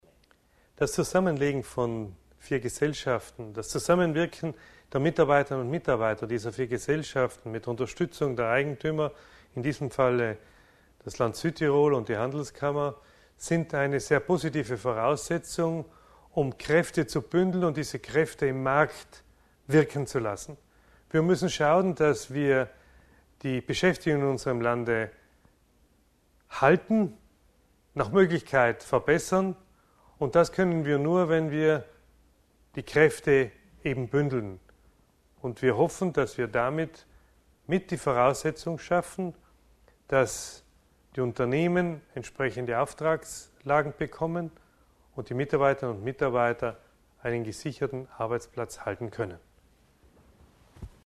Interview Michl Ebner über das Rahmenabkommen zwischen Land und Handelskammer Bozen (Audio)